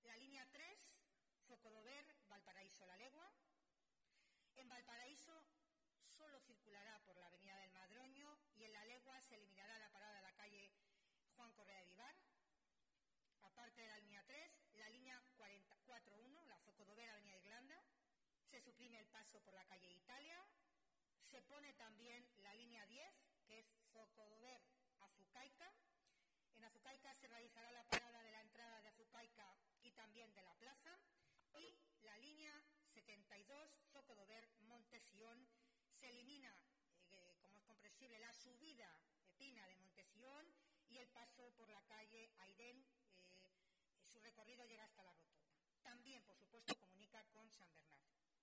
La alcaldesa de Toledo, Milagros Tolón, ha comparecido en rueda de prensa para informar de la última hora del dispositivo extraordinario de limpieza con motivo del temporal, así como de los trabajos que el Ayuntamiento está realizando en la red de alcantarillado y en los cauces de los arroyos de cara al deshielo y de las lluvias previstas para mediados de semana.